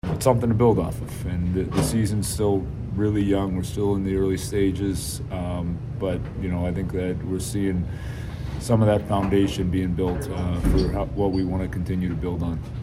Coach Dan Muse says the Penguins did good work out west.